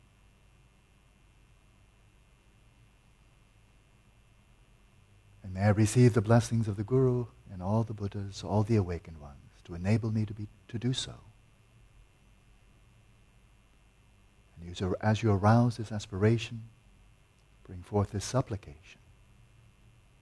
Guided meditations make up a lot of the content, and they are a problem.
During those silent periods, the fluorescent induced hum, the crackling, and buzzing just jump out because of how the material is used - this noise really needs to go away.
It’s far from the noisiest but it’s over the edge of where I would suppress the hum, & the hum is typical.
The clip has tape hiss (no surprise there) and European power buzz (50Hz and harmonics) and also “Stuff.”